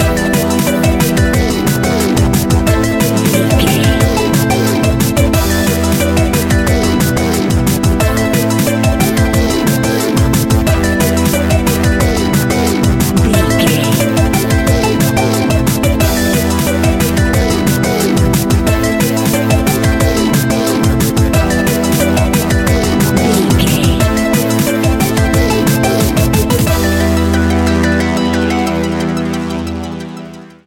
Aeolian/Minor
Fast
futuristic
hypnotic
industrial
dreamy
frantic
aggressive
dark
drum machine
synthesiser
break beat
electronic
sub bass
synth leads
synth bass